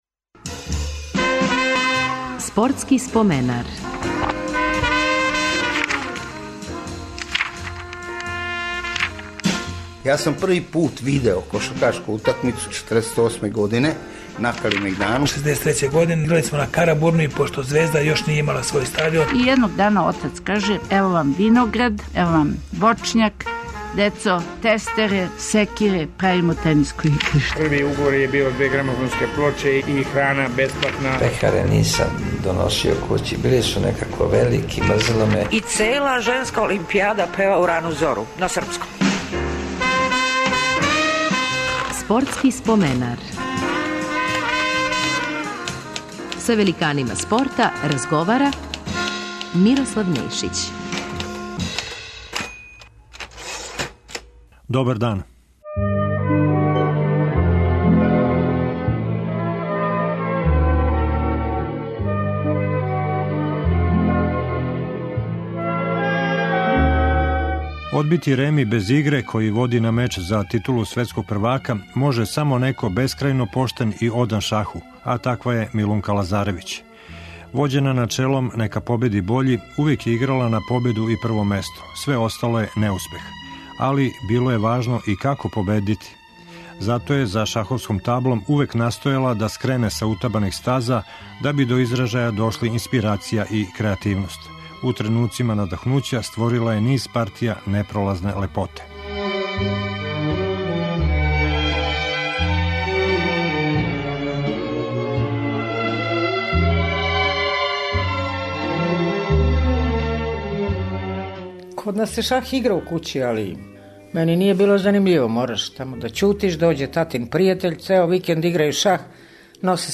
Гост емисије је наша најбоља шахисткиња свих времена Милунка Лазаревић.